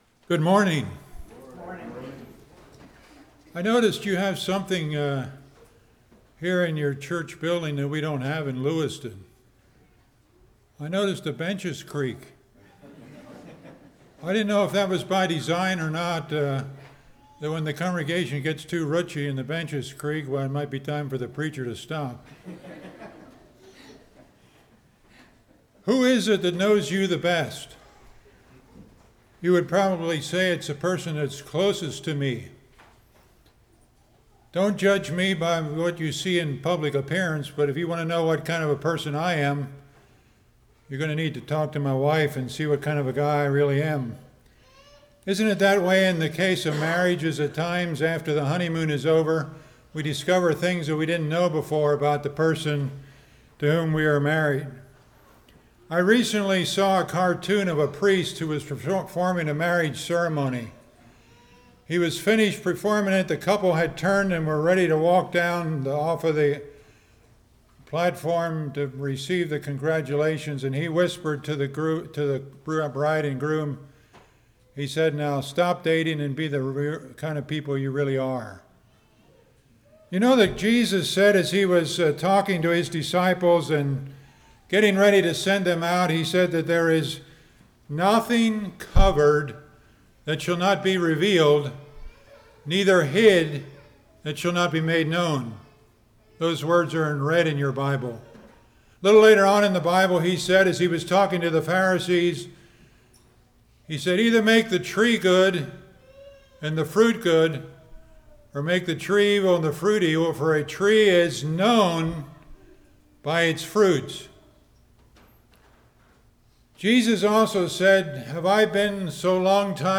Acts 19:1-40 Service Type: Morning Conversion Commitment to Christ Faithfulness « Home Missions How Do We Please God?